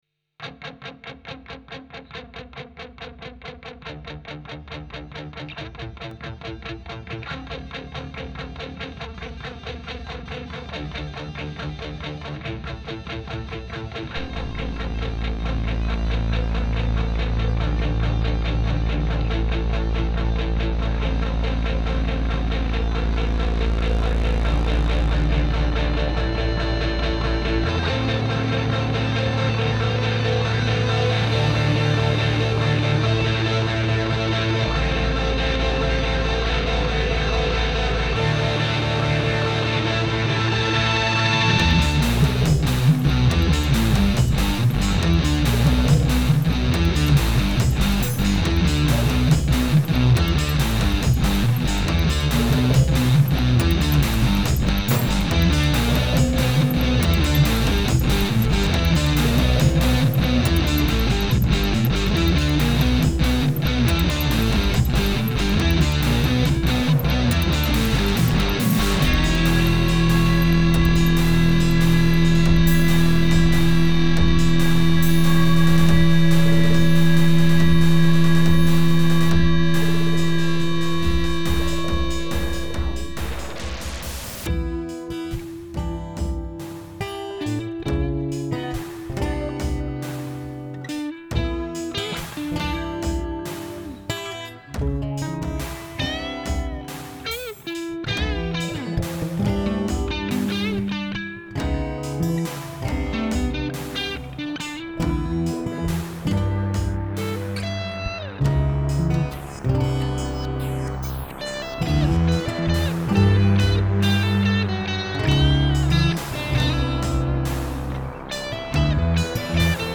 Looking back, I'm not sure that keeping those out of tune guitars was such a great idea but I think laziness prevailed.
Seeing the posts about electribes brought this piece to my mind - The beats are from an ESX going through reaktor.